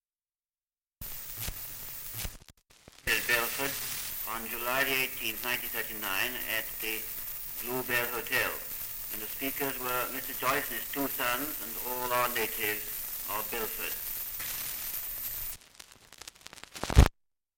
3 - Dialect recording in Belford, Northumberland
78 r.p.m., cellulose nitrate on aluminium